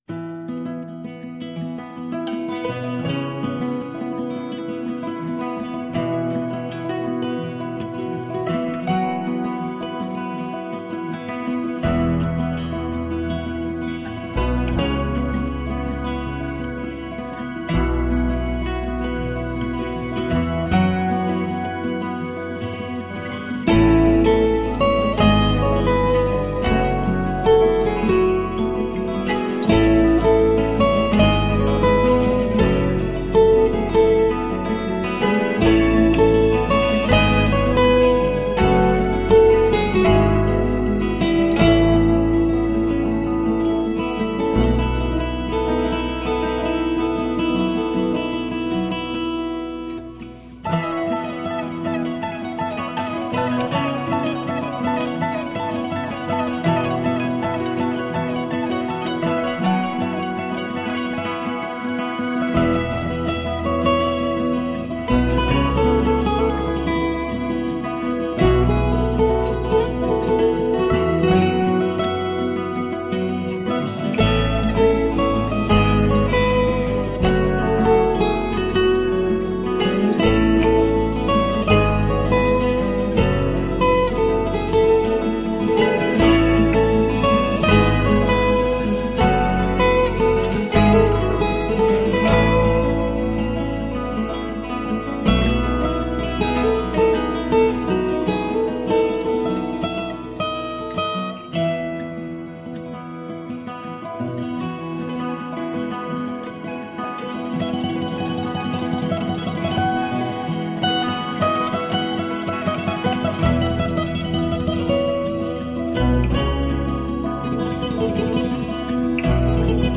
Relaxing acoustic guitar duets.
lead acoustic guitar